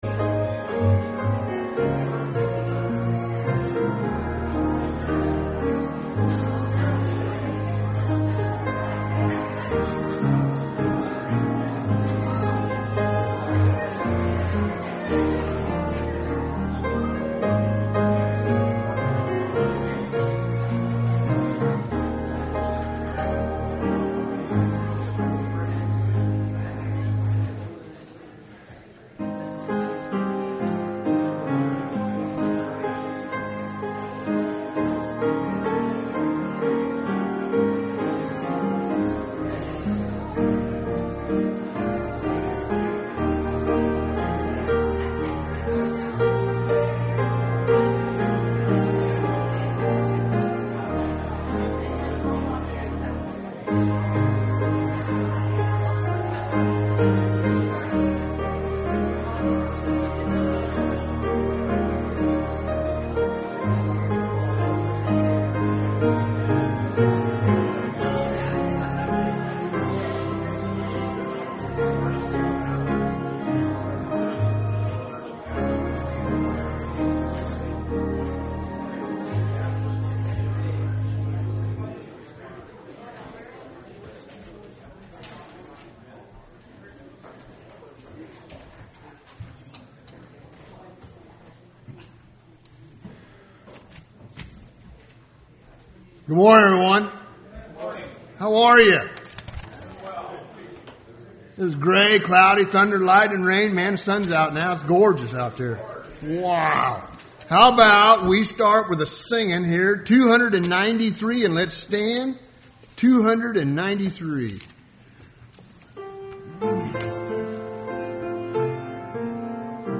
Service Type: Sunday Morning Service Topics: Christian Living , Revival